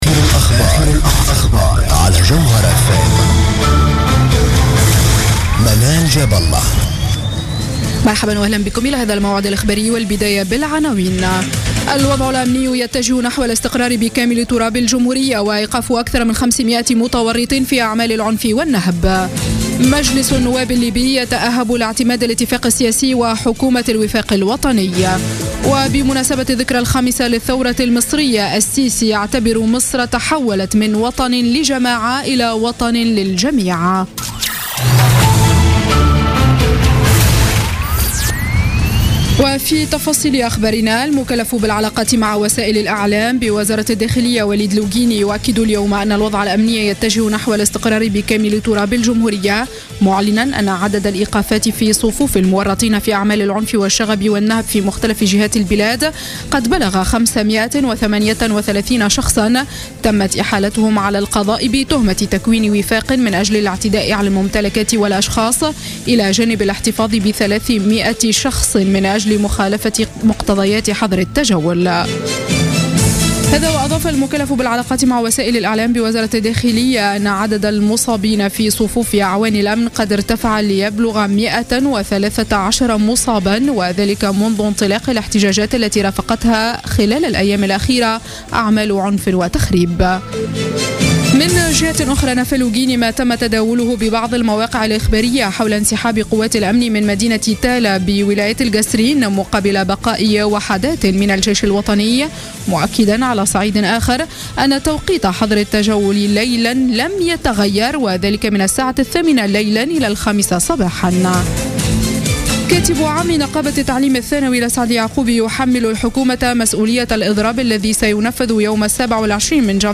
نشرة أخبار السابعة مساء ليوم الأحد 24 جانفي 2015